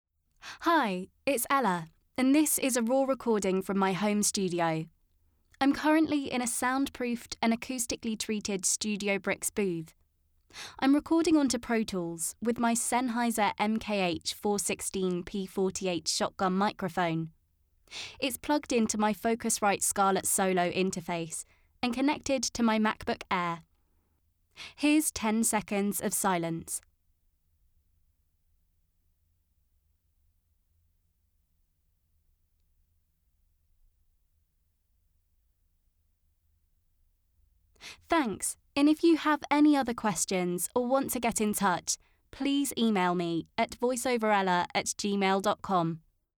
Female
My voice is warm, smooth and clear with a playing age from teens to early 30s.
Raw Recording From Home Studio
Words that describe my voice are Warm, Youthful, Clear.
1113Studio_Raw_Recording.mp3